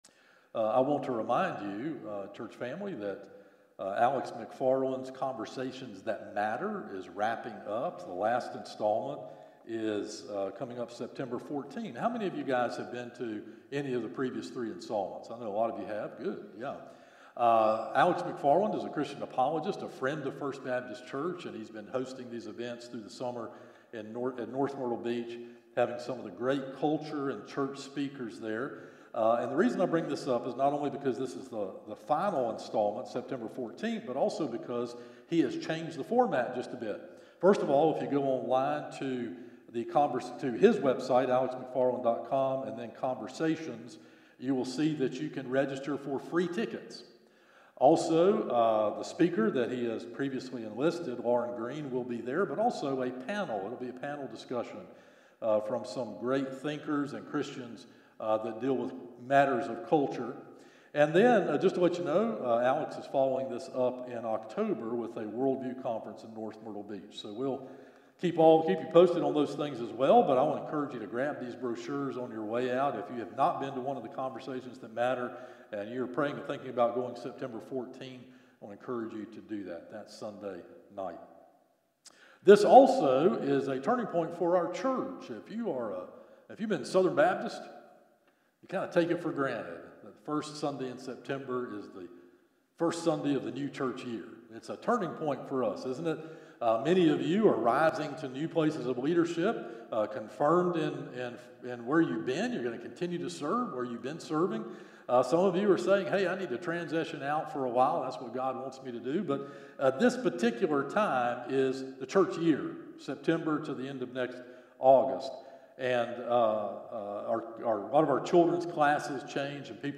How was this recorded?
Morning Worship - 11am Passage